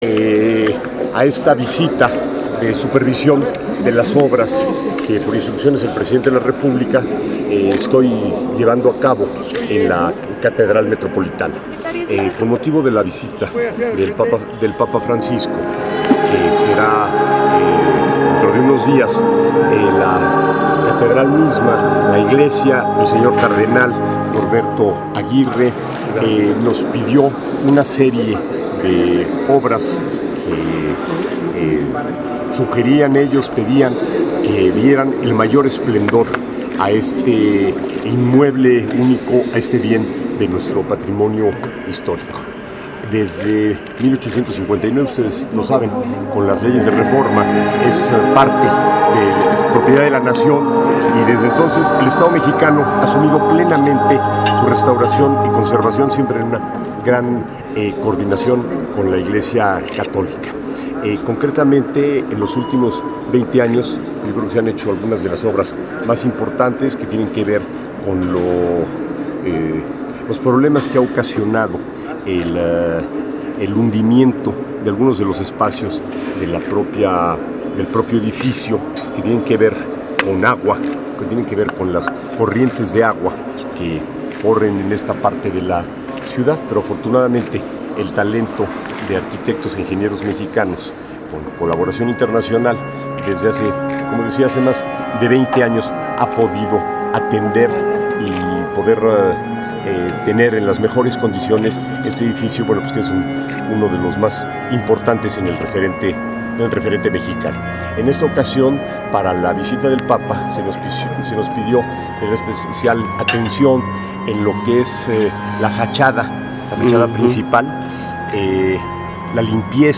rafael_tovar_y_de_teresa__secretario_de_cultura.mp3